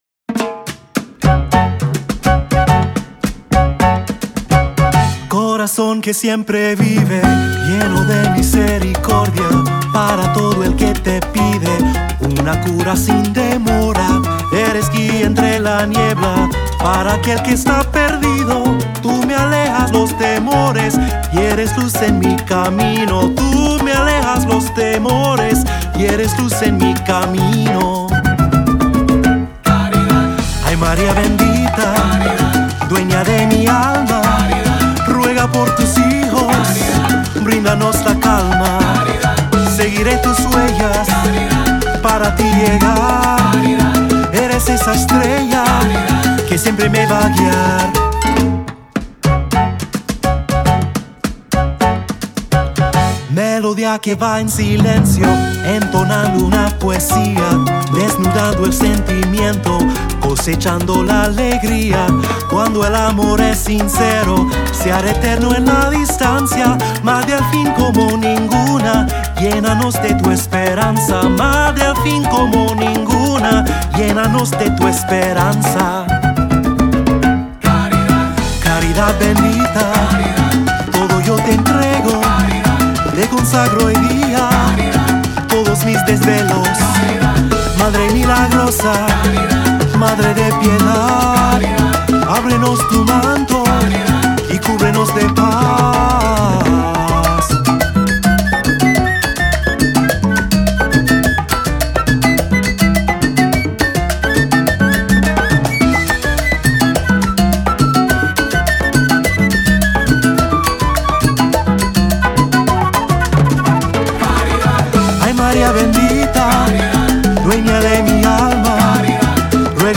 Voicing: Three-part; Cantor; Assembly